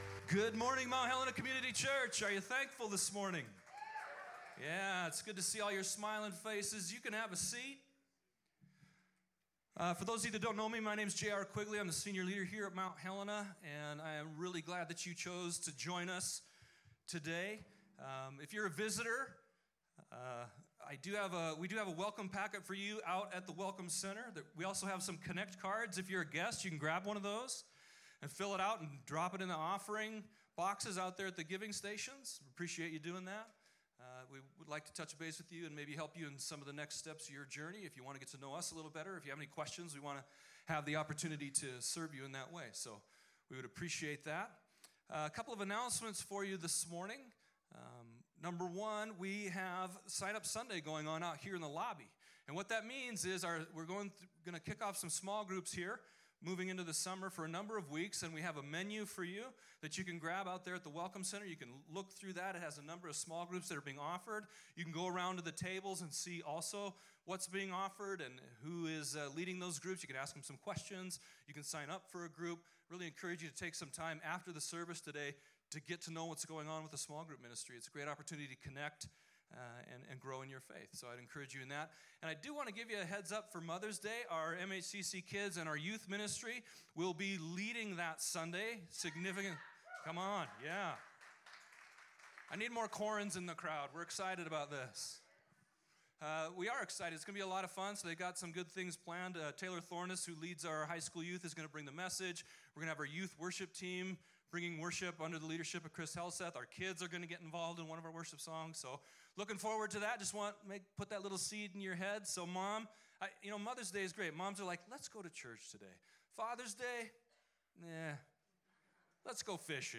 Baptism Sunday Service - Mount Helena Community Church
baptism-sunday-service.mp3